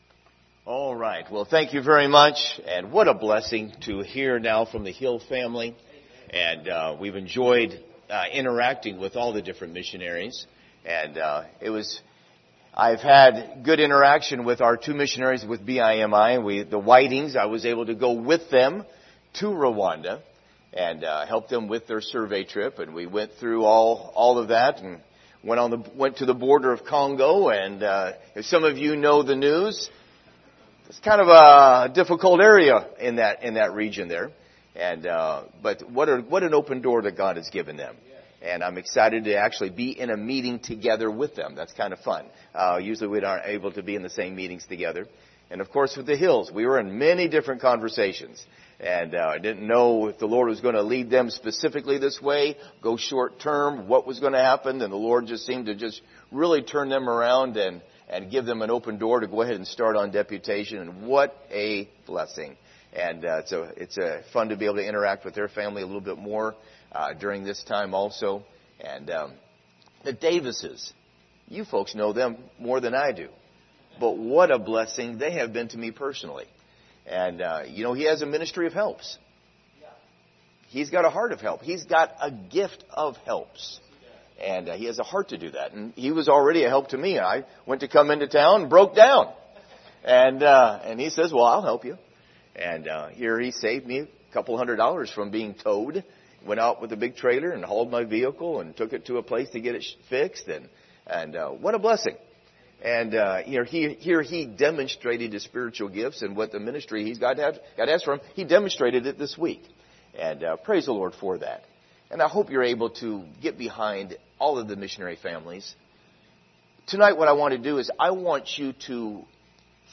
Speaker: Missionary Speaker
Service Type: Special Service Topics: missions , prayer